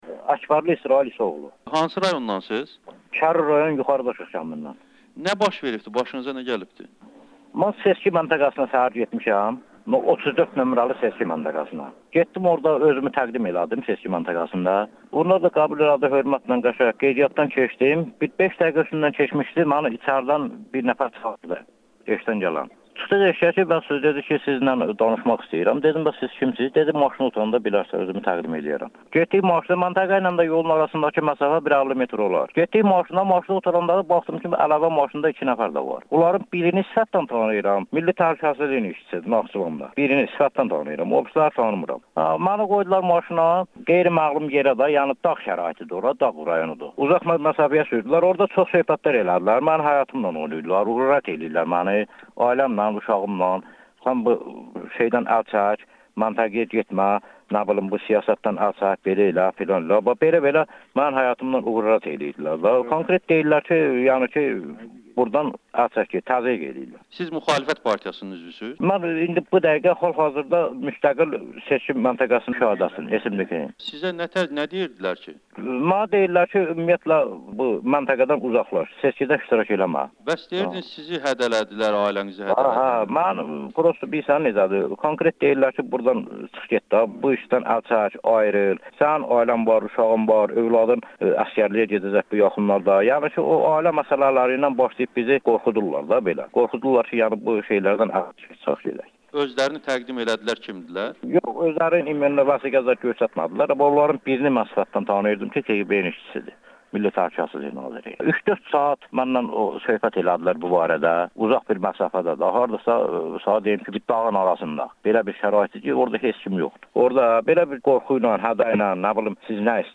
Naxçıvanda müstəqil müşahidəçi oğurlanaraq hədələnib [Audio-Müsahibə]